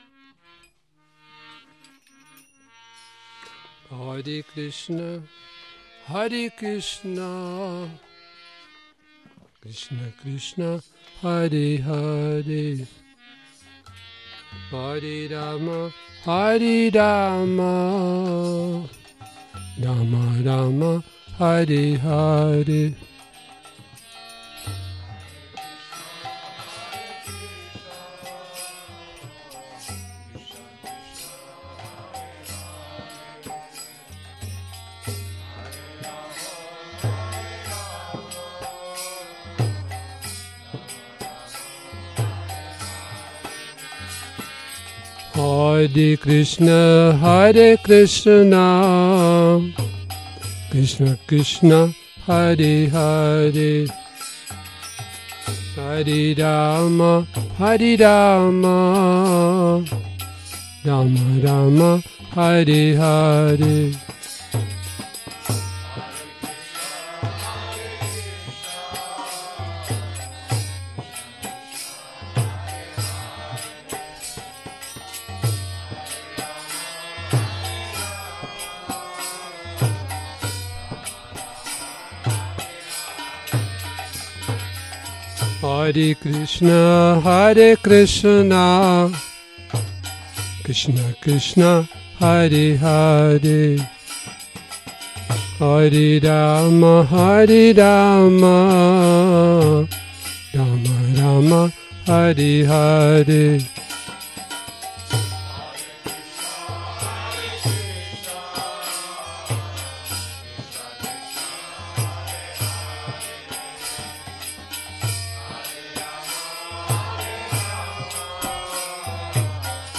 Kírtan Nedělní program